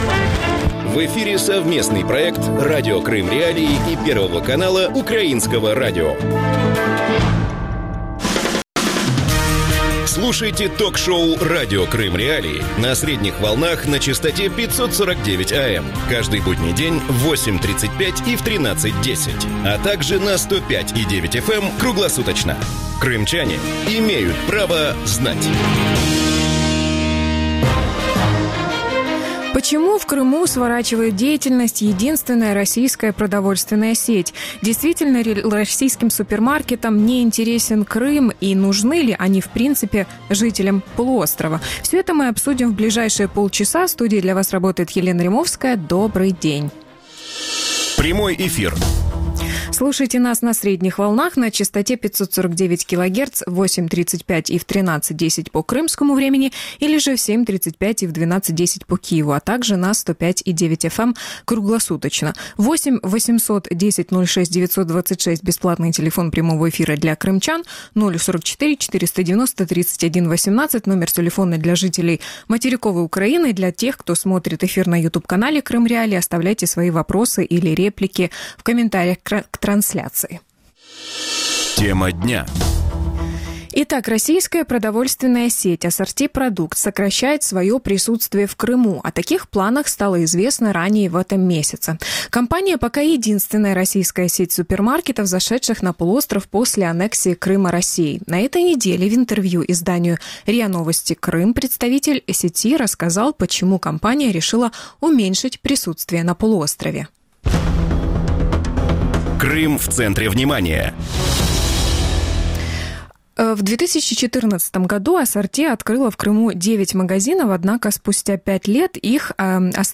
Почему в Крыму сворачивает деятельность единственная российская продовольственная сеть Ассорти-Продукт? Почему российским супермаркетам не интересен Крым? Решит ли вопрос продуктового разнообразия в Крыму Керченский мост? Гости эфира